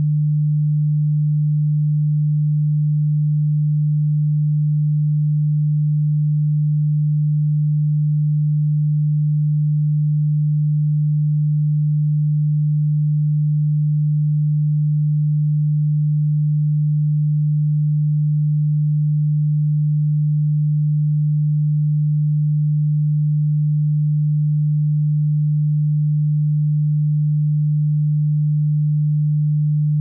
Sound used in the game - raw_sound.ogg
raw_sound.ogg